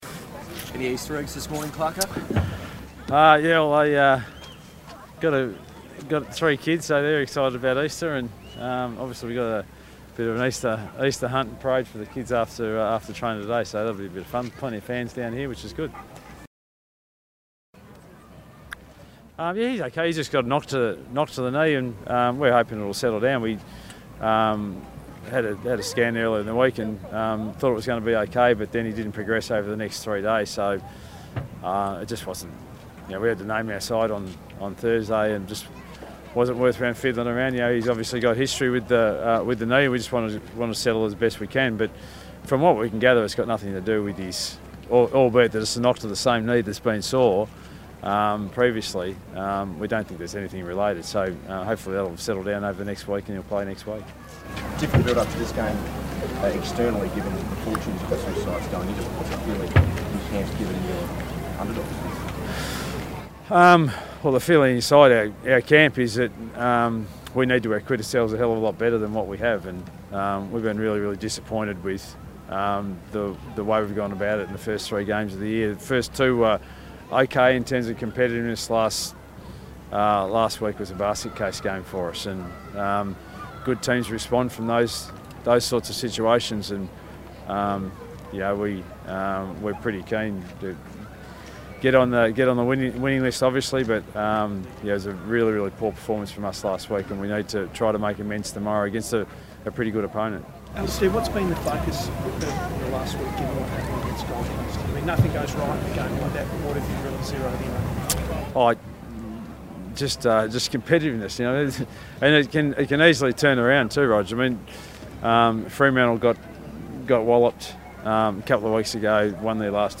Clarkson Press Conference 16-04-17